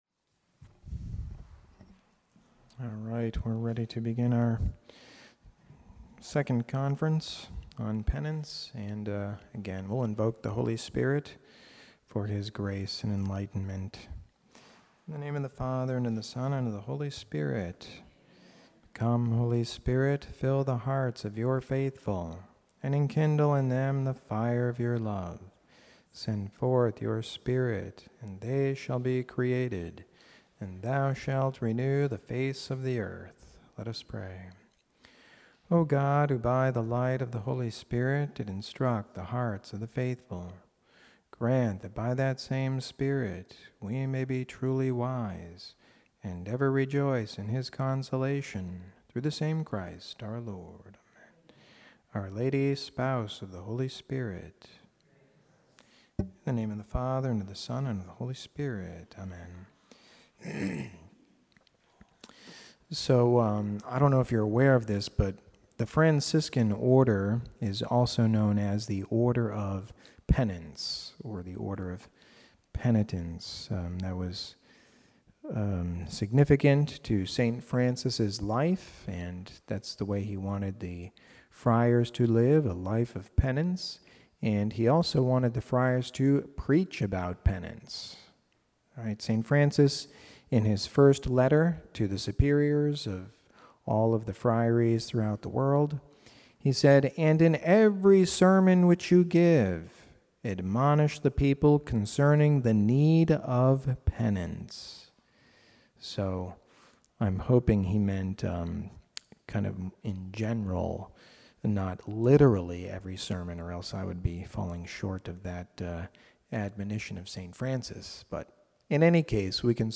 Lenten Reflection